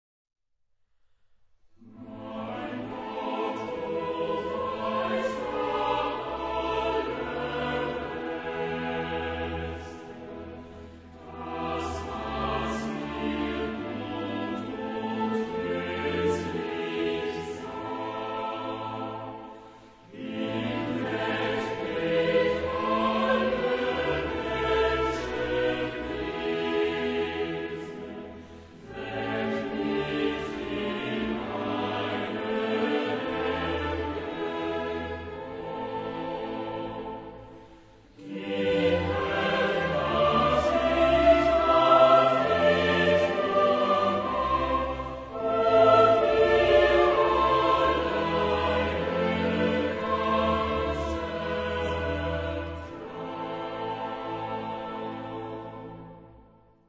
Choralkantate
Genre-Style-Forme : Romantique ; Sacré ; Cantate de chorals
Type de choeur : SATB  (4 voix mixtes )
Solistes : Sopran (1)  (1 soliste(s))
Instrumentation : Orchestre à cordes  (5 partie(s) instrumentale(s))
Instruments : Violon (2) ; Alto (1) ; Violoncelle (1) ; Contrebasse (1)
Tonalité : la mineur